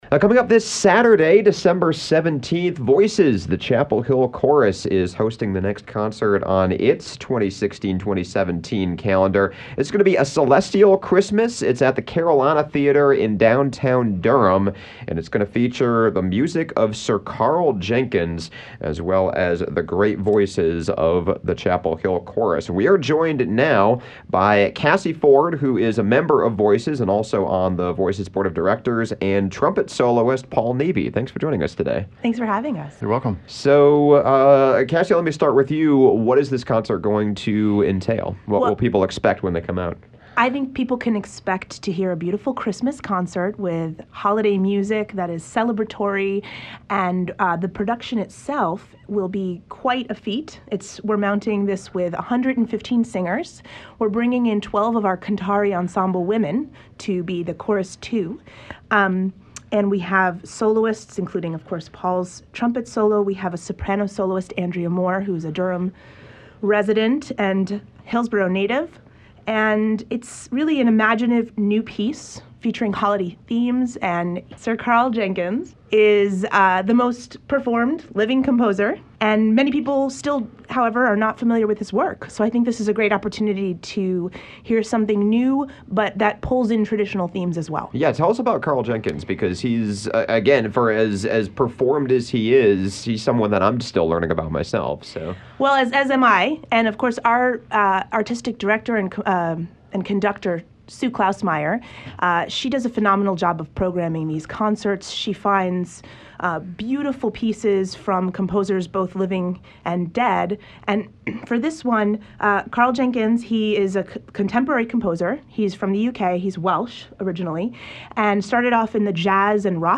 trumpet soloist